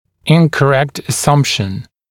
[ˌɪnkə’rekt ə’sʌmpʃn][ˌинкэ’рэкт э’сампшн]неправильное предположение, неверное предположение